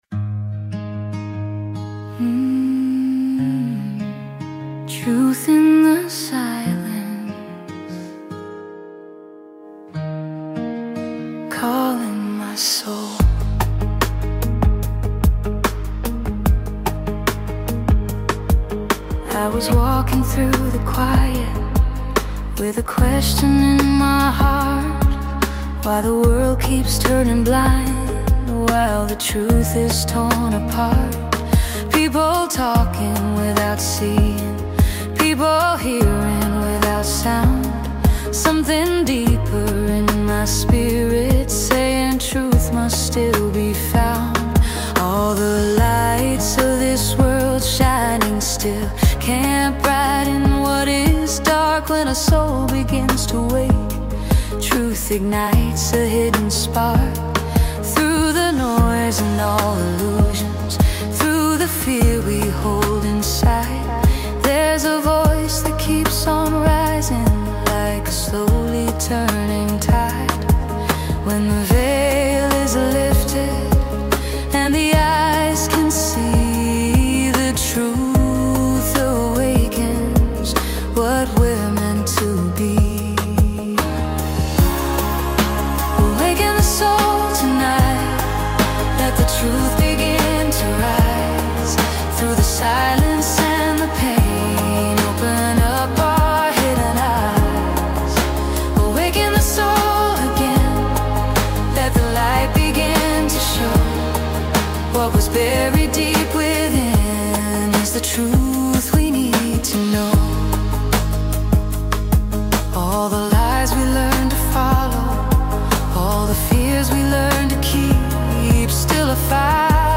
As it continues, the feeling becomes more immersive.